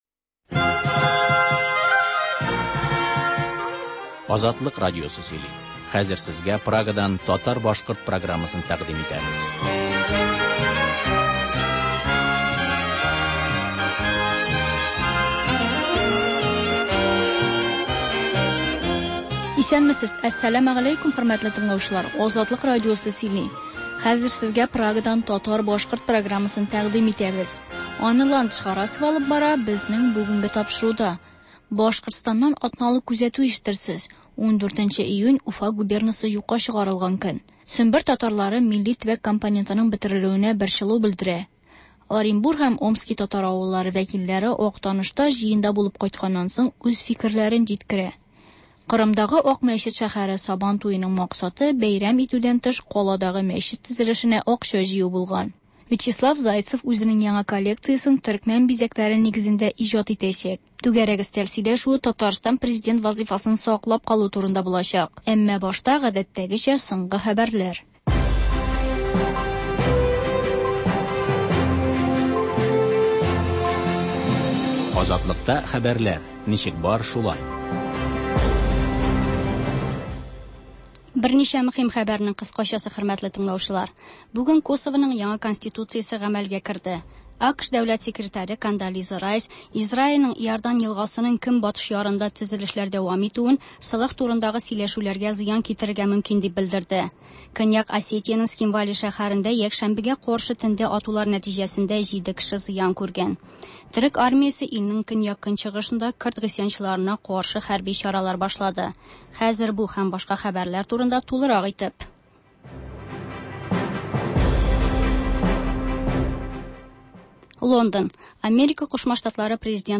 Азатлык радиосы бар атнага күз сала - соңгы хәбәрләр - Башкортстаннан атналык күзәтү - түгәрәк өстәл артында сөйләшү